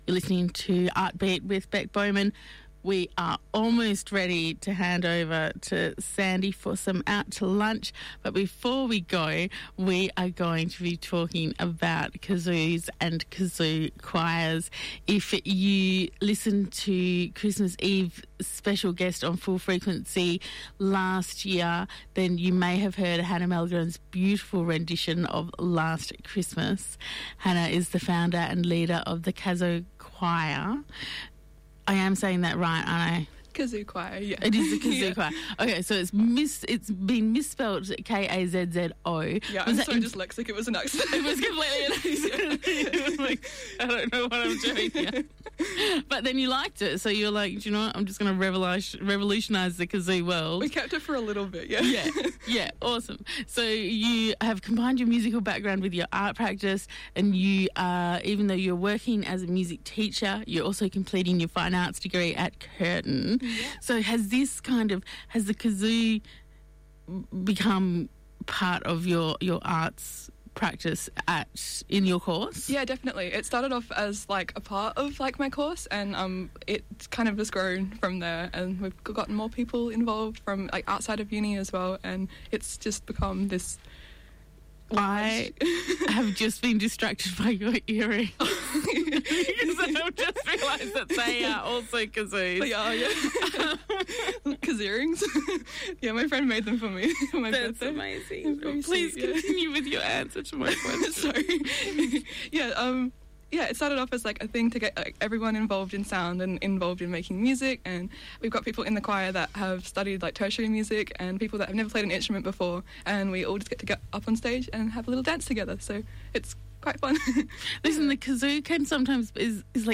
Together they sit down to discuss how the group was formed, including what the appeal of the kazoo instrument is towards the public, and how the choir chooses their repertoire.